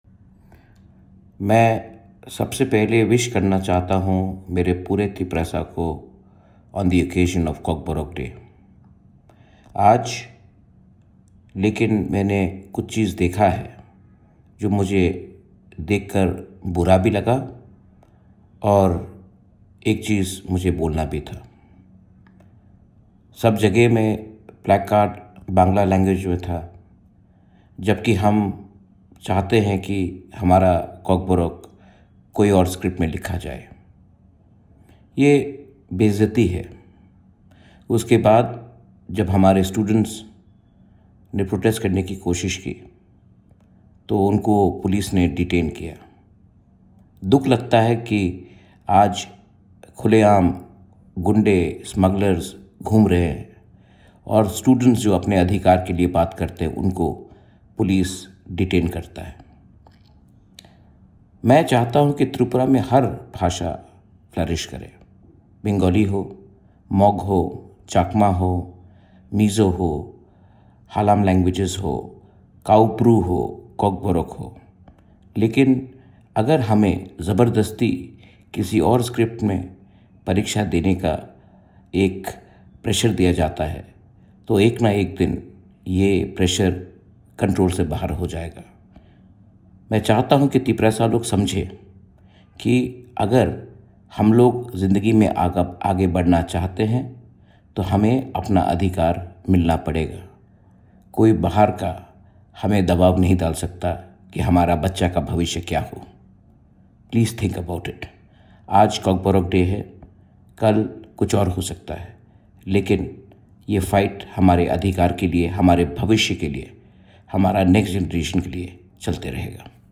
Agartala, Jan 19: On the occasion of the 48th Kokborok Day, Tripura royal scion and Tipra Motha Party founder Pradyot Manikya Debbarma shared an audio message on social media, extending greetings to the people of Tiprasa while strongly voicing concerns over the ongoing language and script-related issues in the state.